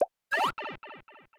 Sound effect of Pipe Slide Enter (World Map) in Super Mario Bros. Wonder
SMBW_Pipe_Slide_Enter_World_Map.oga